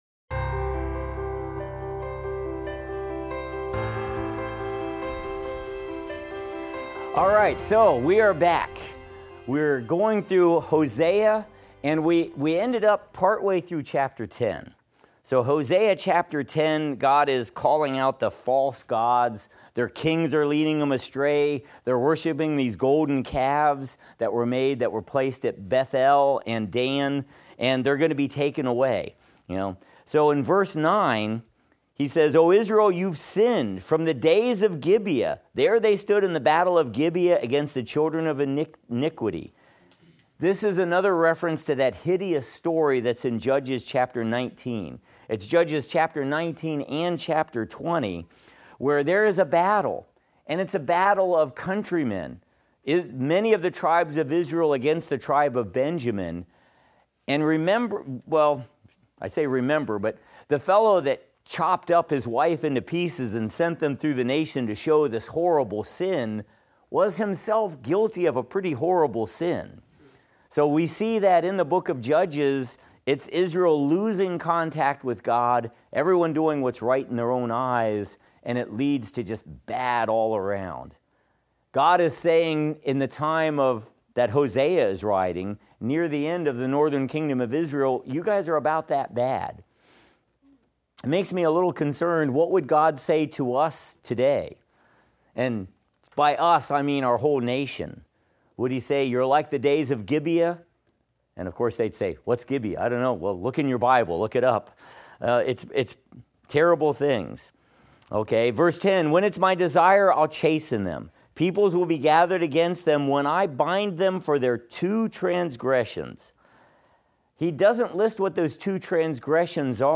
Minor Prophets - Lecture 5 - audio.mp3